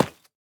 Minecraft Version Minecraft Version 1.21.5 Latest Release | Latest Snapshot 1.21.5 / assets / minecraft / sounds / block / tuff_bricks / place5.ogg Compare With Compare With Latest Release | Latest Snapshot
place5.ogg